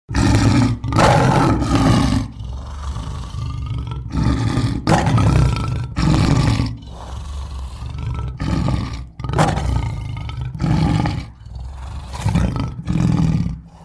دانلود آهنگ حیوانات 34 از افکت صوتی انسان و موجودات زنده
دانلود صدای حیوانات 34 از ساعد نیوز با لینک مستقیم و کیفیت بالا
جلوه های صوتی
برچسب: دانلود آهنگ های افکت صوتی انسان و موجودات زنده دانلود آلبوم مجموعه صدای حیوانات مختلف با سبکی خنده دار از افکت صوتی انسان و موجودات زنده